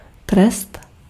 Ääntäminen
Ääntäminen US : IPA : ['pʌn.ɪʃ.mənt]